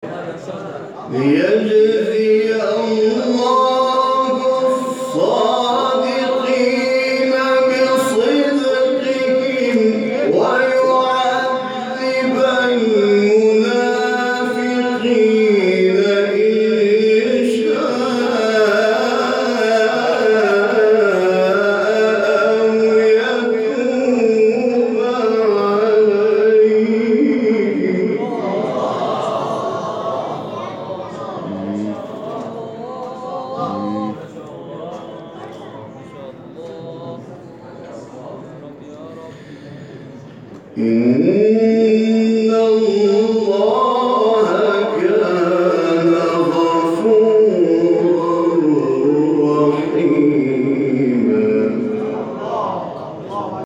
شبکه اجتماعی: فرازهای صوتی از تلاوت قاریان برجسته و ممتاز کشور را که به‌تازگی در شبکه‌های اجتماعی منتشر شده است، می‌شنوید.